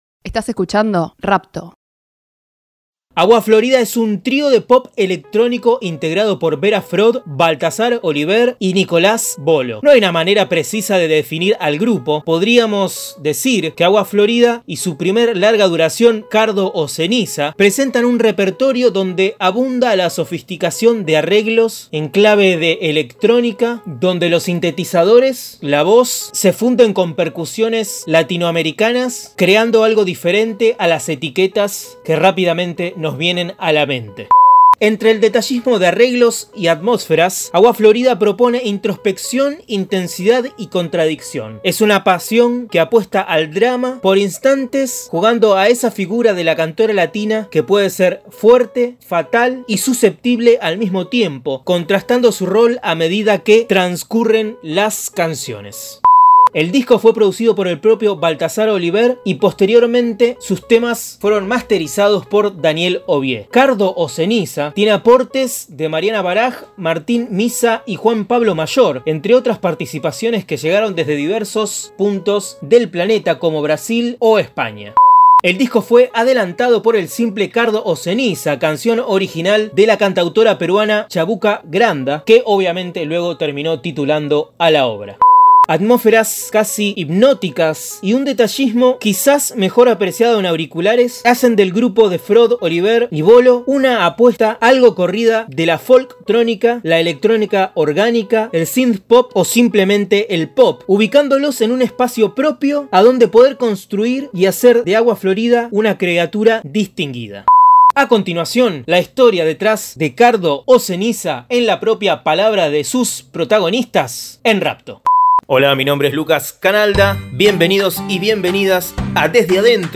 Desde adentro es un podcast que busca revelar las instancias de producción de las últimas novedades de la música independiente. Los discos más recientes desde la voz de sus protagonistas.